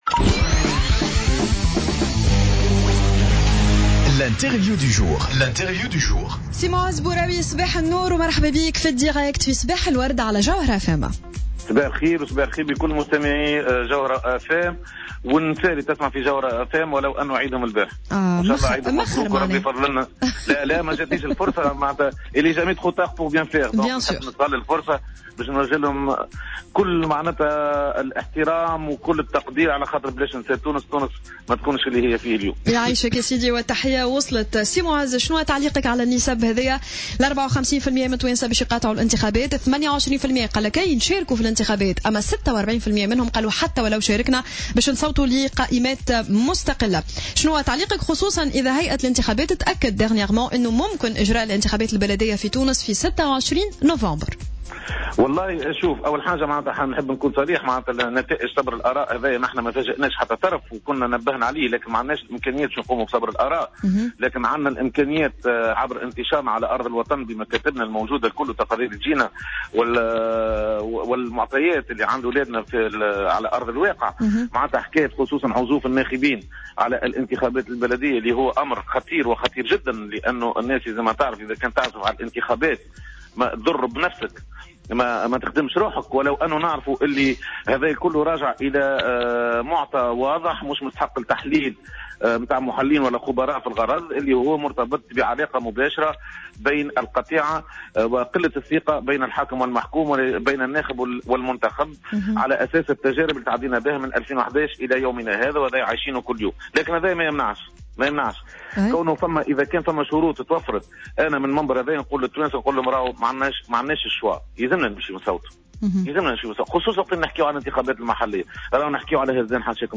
Intervenu jeudi matin sur les ondes de Jawhara FM